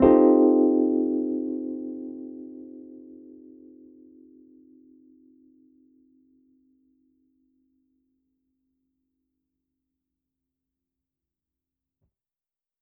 JK_ElPiano3_Chord-Cm13.wav